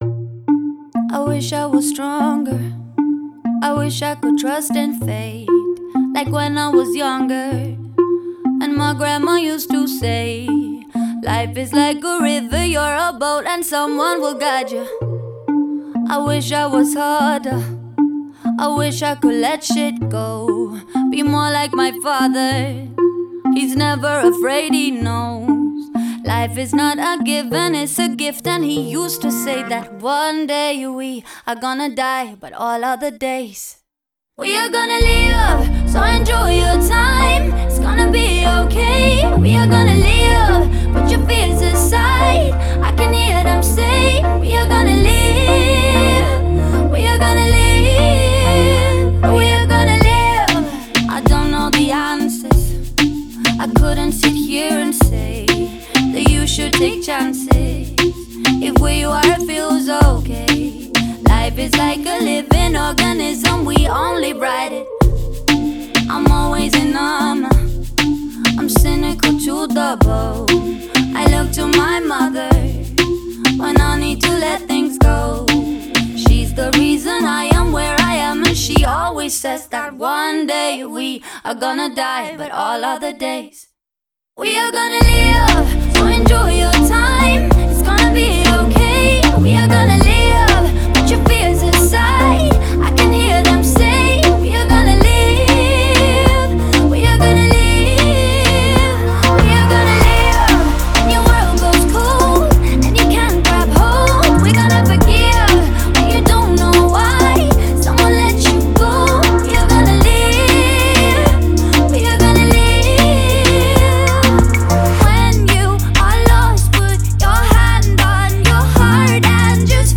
вдохновляющая поп-песня
яркий вокал